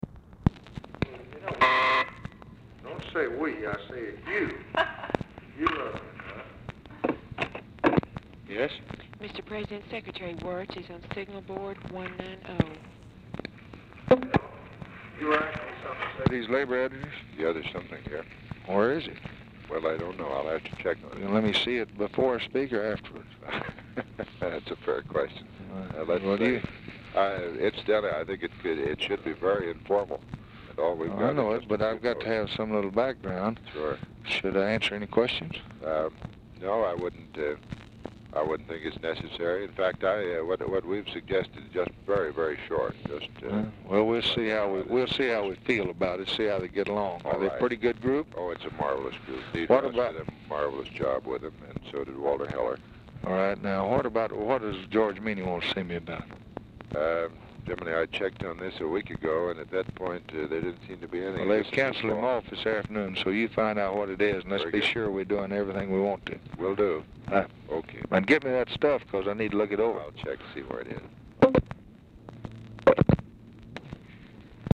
Oval Office or unknown location
Telephone conversation
Dictation belt